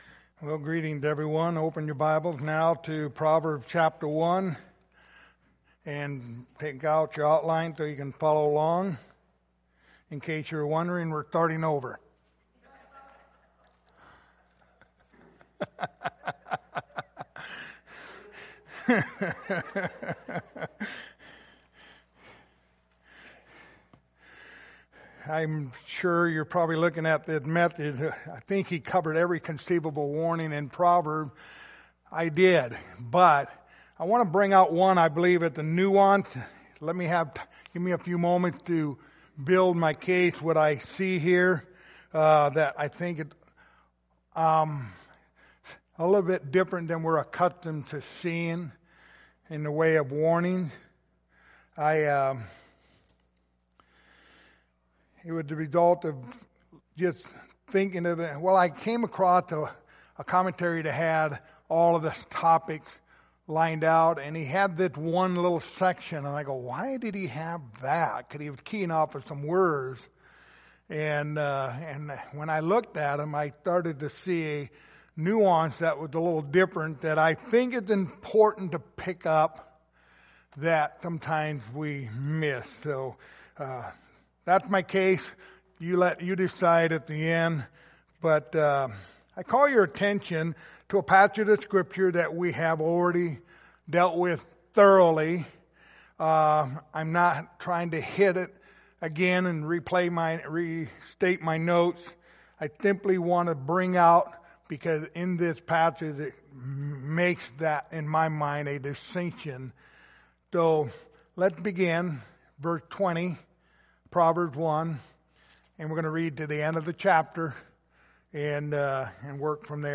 The Book of Proverbs Passage: Proverbs 1:24-27 Service Type: Sunday Evening Topics